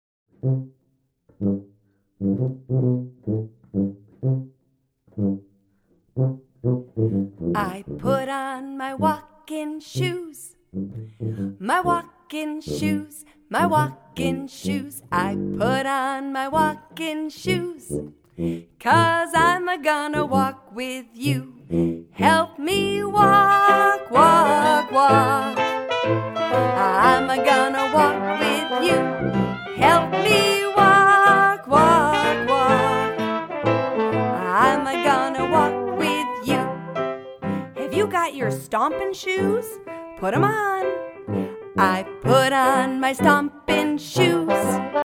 full Dixieland Band